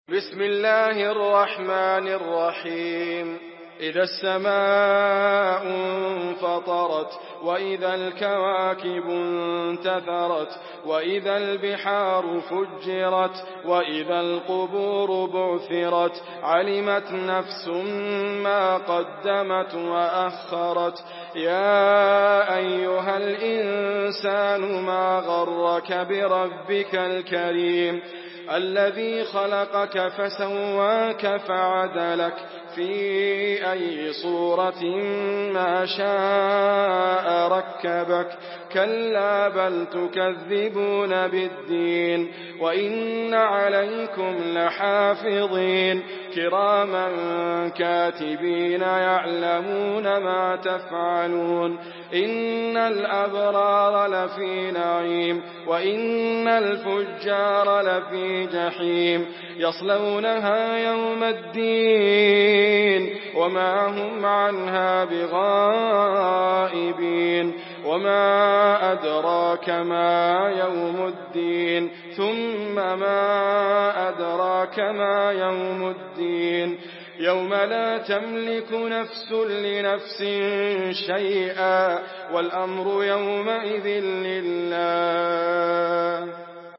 Surah Infitar MP3 by Idriss Abkar in Hafs An Asim narration.
Murattal